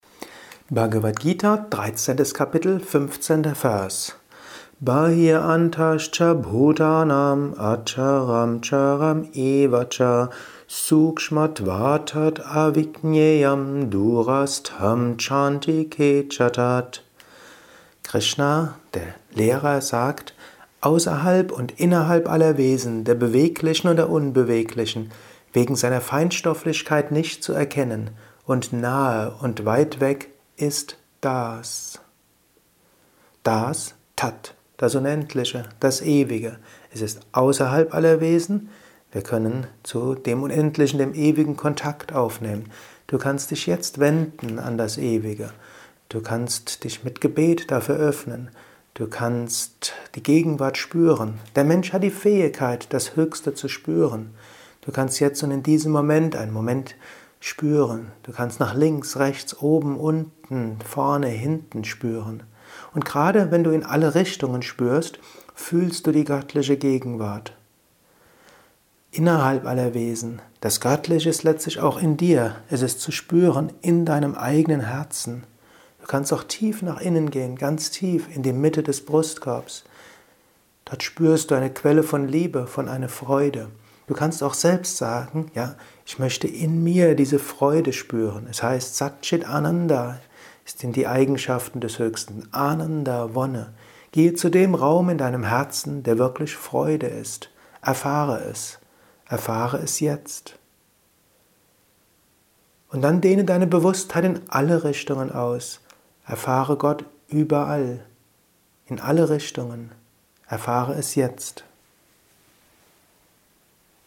Kurzvorträge
Aufnahme speziell für diesen Podcast.